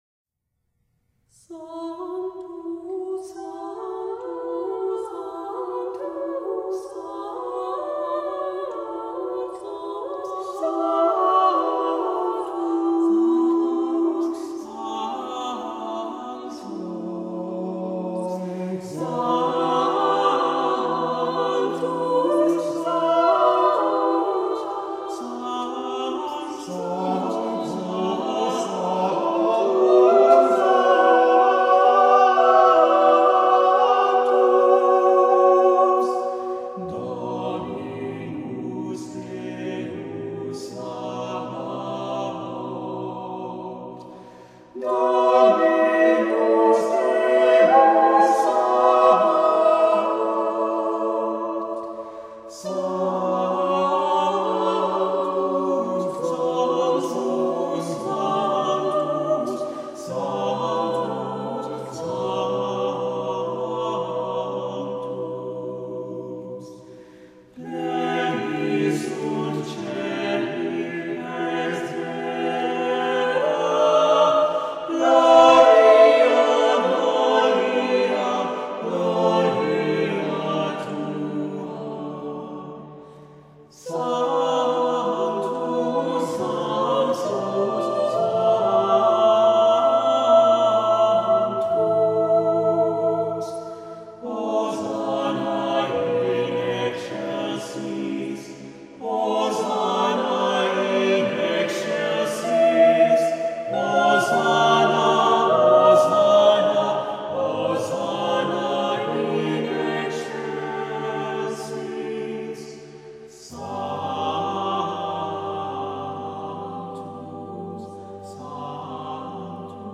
Choral Music
• SATB